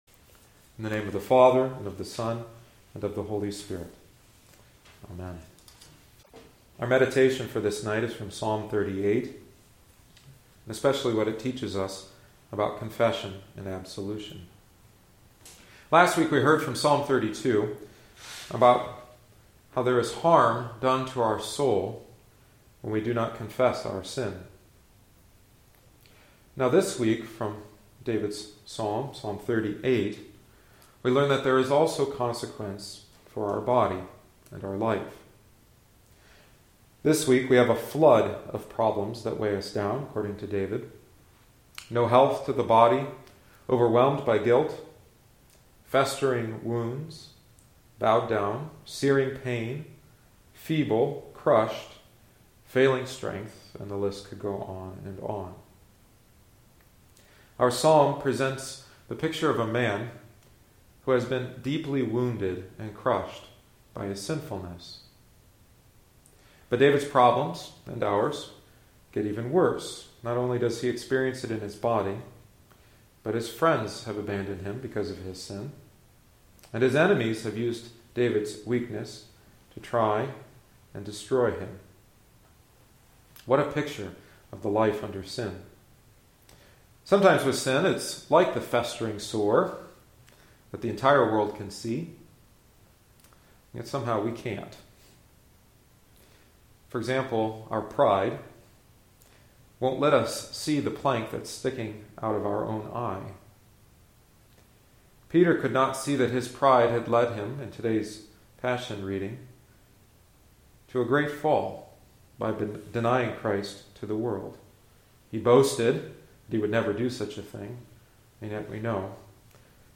Audio Only. in Sermons | 21 February, 2013 | 26 Words | Comment